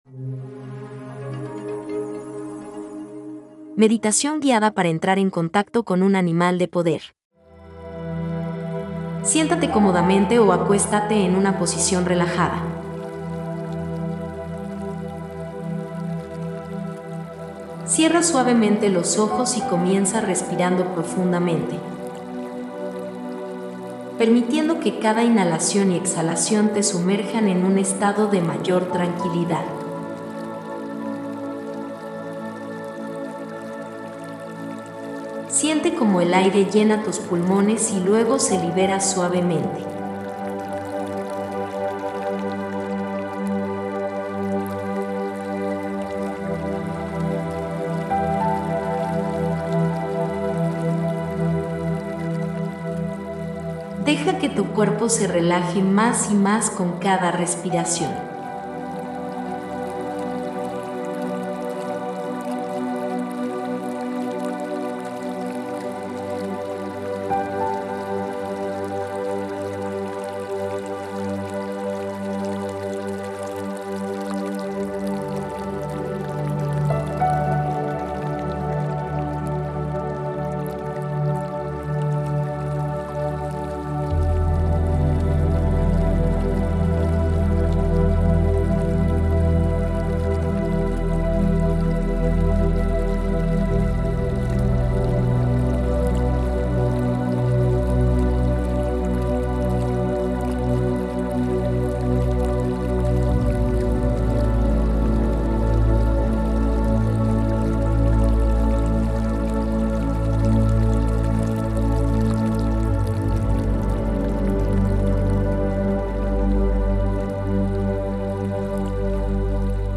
Elige si prefieres escuchar el audio de la meditación o ver el video